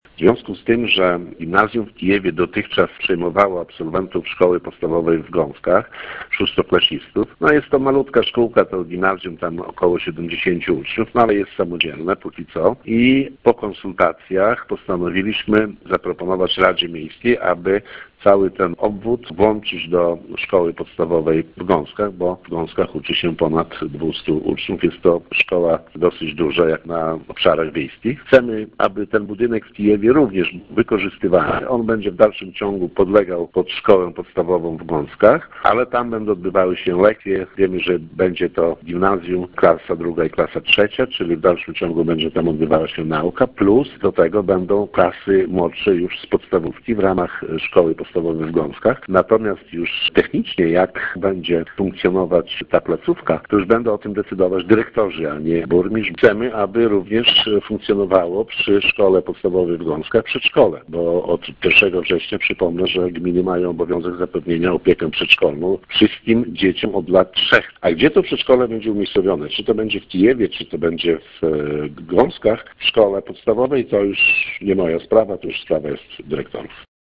Na takie rozwiązanie nie zgadzają się mieszkańcy miejscowości Świdry i proponują, by do Kijewa przenieść część oddziałów ze Szkoły Podstawowej w Gąskach- mówi Radiu 5 sołtys wsi Świdry Ryszard Makarewicz.
Jak dodaje burmistrz utworzenie w Kijewie samodzielnej szkoły podstawowej ze względu na niewielką ilość uczniów doprowadziłoby do zamknięcia jej w przeciągu dwóch lat- tłumaczy.